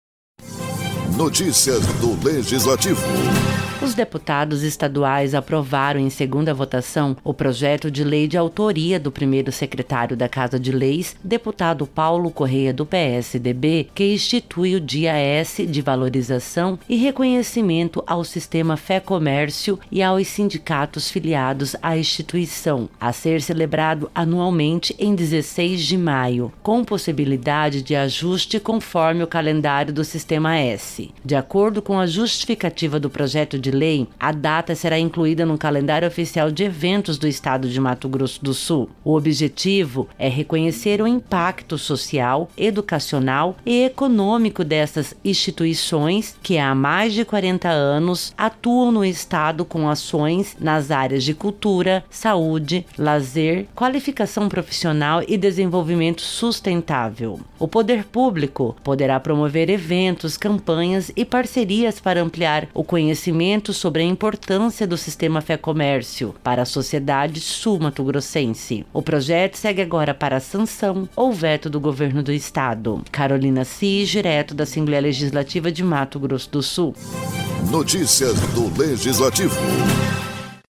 Durante sessão ordinária, os deputados estaduais aprovam em segunda votação o Projeto de Lei 49/2025, de autoria do deputado Paulo Corrêa (PSDB), institui o “Dia S” de valorização e reconhecimento ao Sistema Fecomércio/SESC/SENAC/IPF e Sindicatos filiados à Federação do Comércio de Bens, Serviços e Turismo do estado de Mato Grosso do Sul (Fecomercio-MS), a ser comemorado, anualmente, no dia 16 de maio.